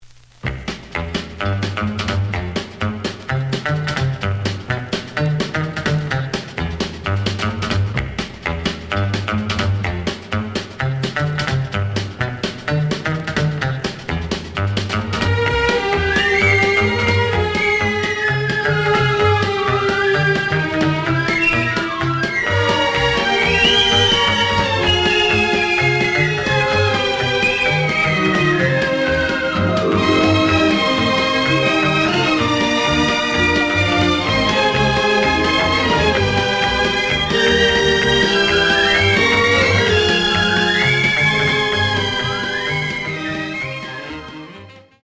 The audio sample is recorded from the actual item.
●Genre: Jazz Other
B side plays good.